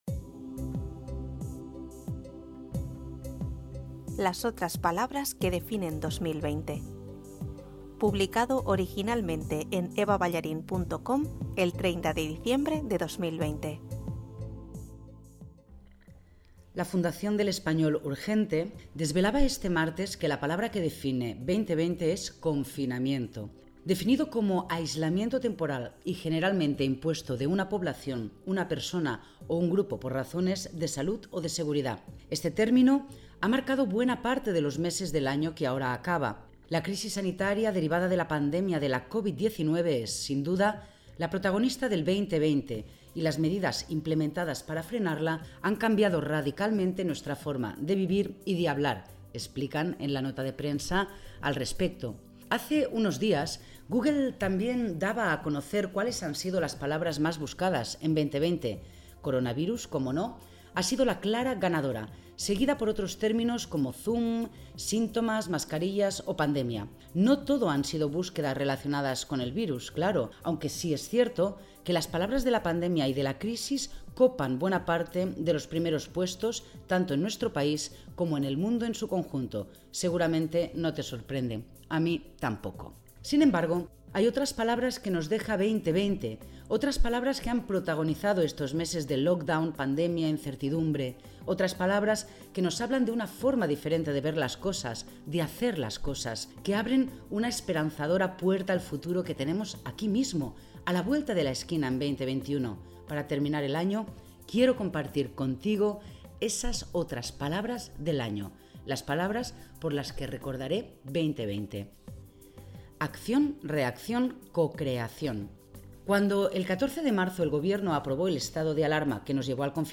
Si lo prefieres, te lo leo